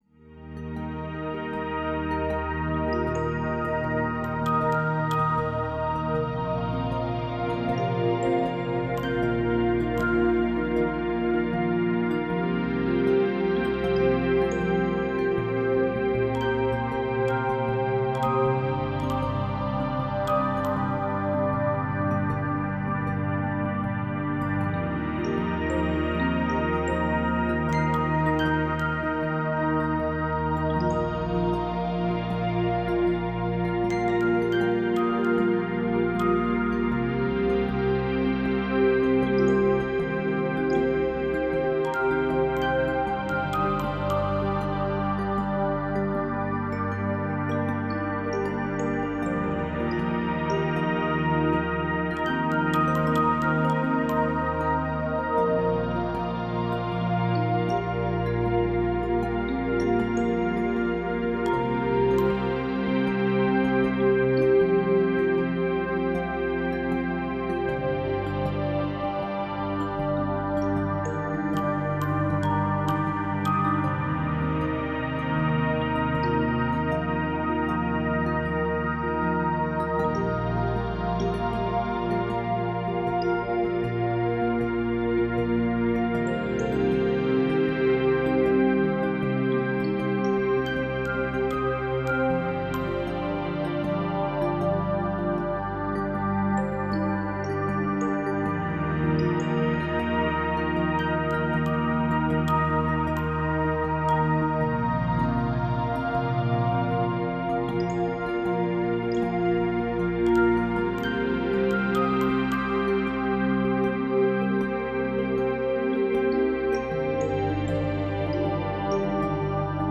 Genre: New Age, Ambient, Relax.